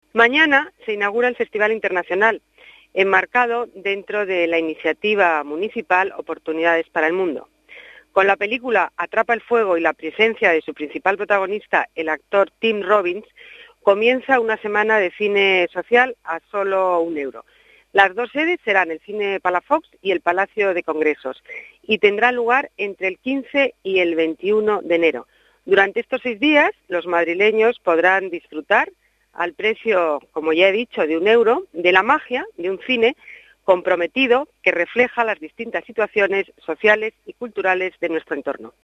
Nueva ventana:Declaraciones de Ana Botella sobre el festival de Cine Solidario